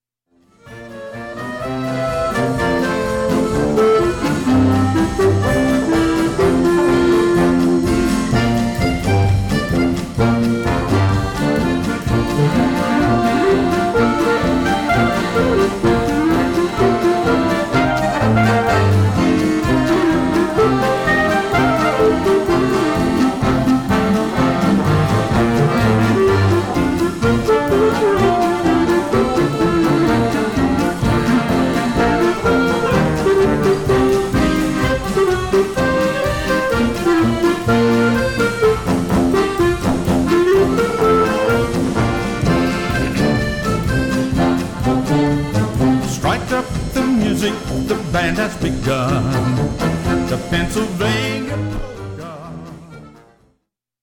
accordion, tuba, guitar, and drums